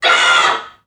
NPC_Creatures_Vocalisations_Robothead [18].wav